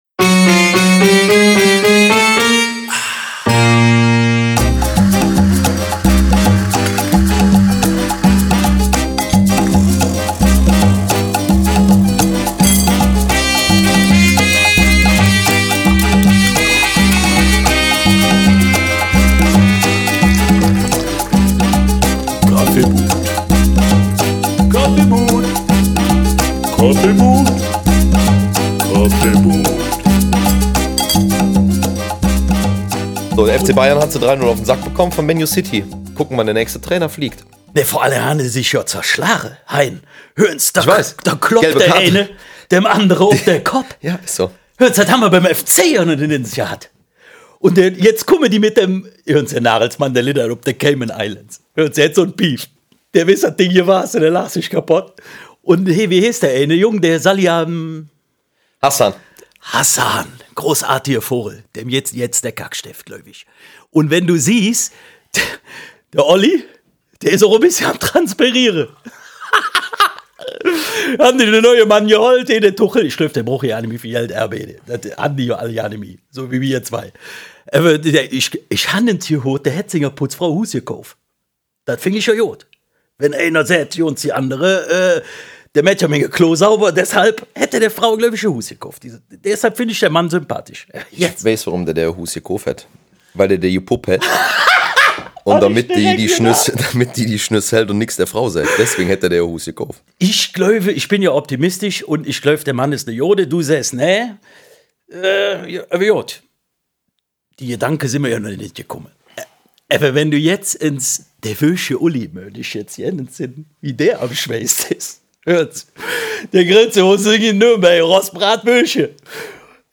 Ne Podcast op kölsch. Welche Themen bewegen die Welt und natürlich unser heißbeliebtes Köln?